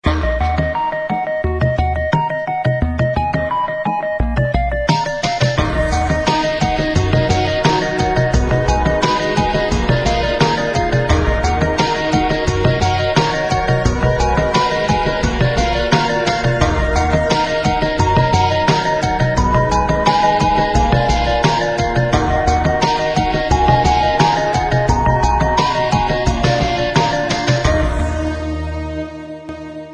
背景音乐为紧张急促的背景音乐
该BGM音质清晰、流畅，源文件无声音水印干扰